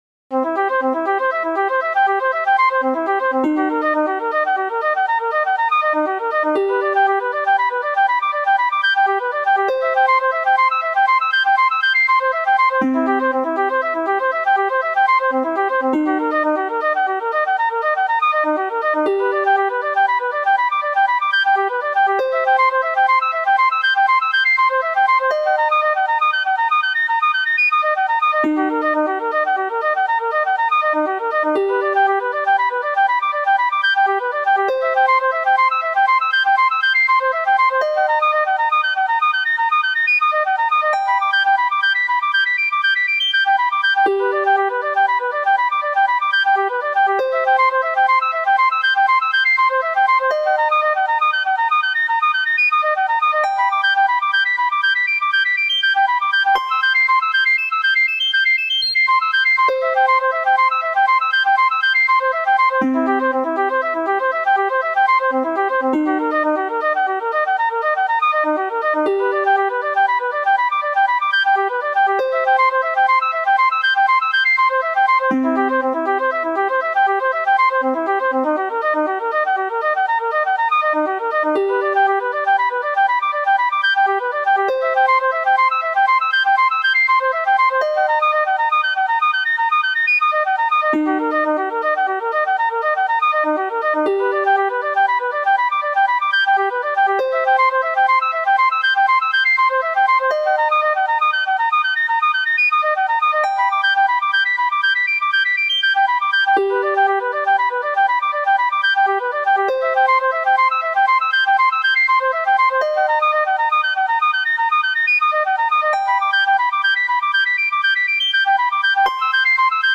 These mp3s were rendered to audio with the Roland Sound Canvas.
guitar divisions [4 mins] guitar recorder tubular bells [4 mins] harp bluesy minor flute major
So, in these recordings, they fade out at the end of the clip.
harp_bluesy_minor_flute_major_4_mins.mp3